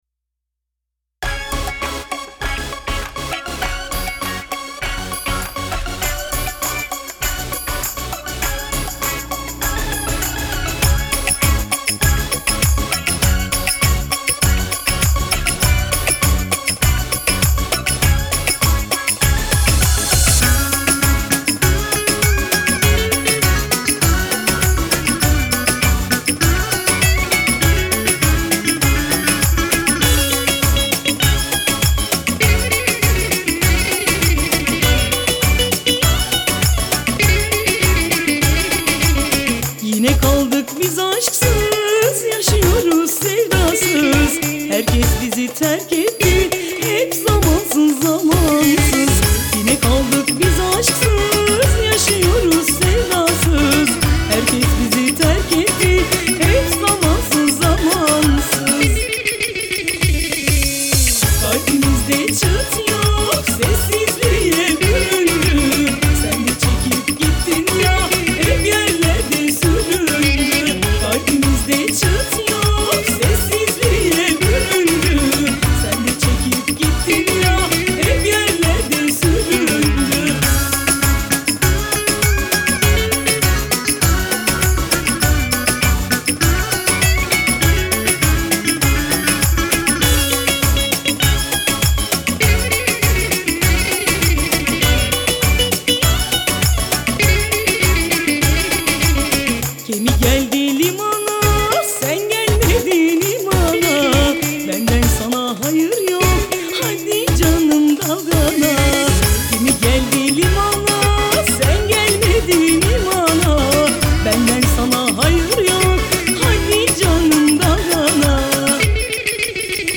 Трек размещён в разделе Турецкая музыка.